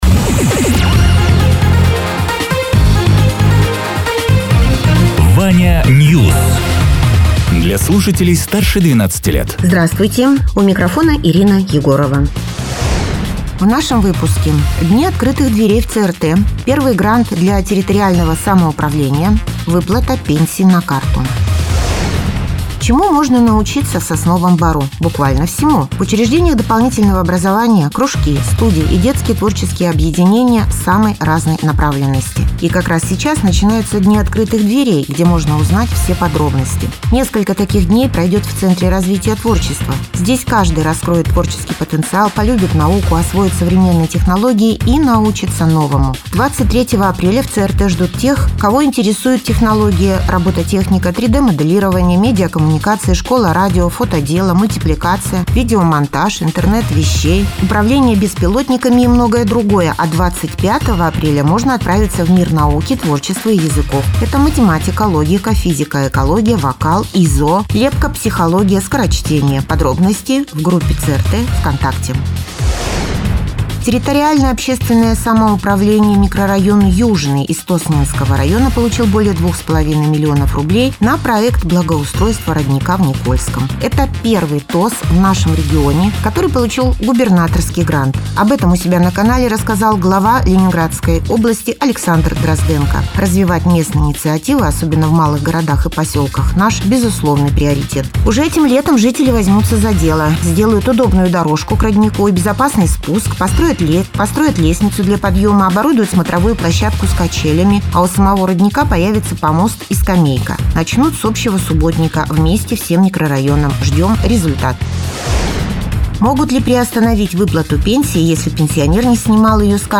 Радио ТЕРА 24.04.2026_08.00_Новости_Соснового_Бора